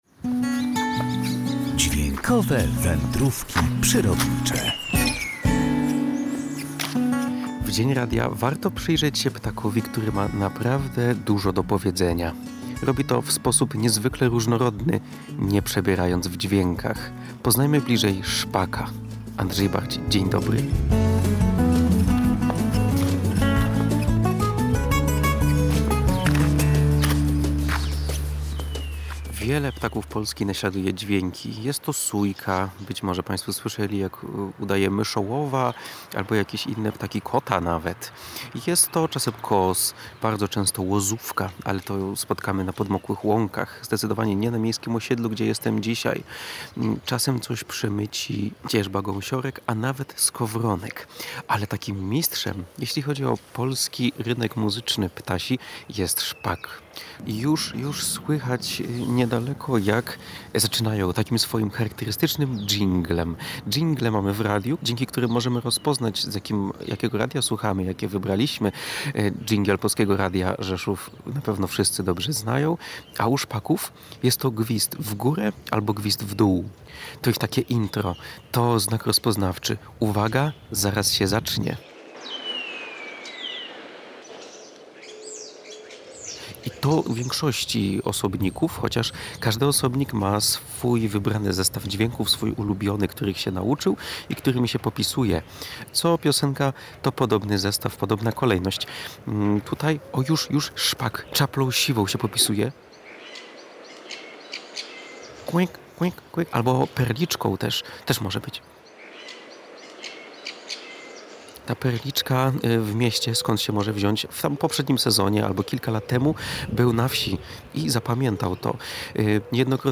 Robi to w sposób niezwykle różnorodny, nie przebierając w dźwiękach.
Bohater odcinka – szpak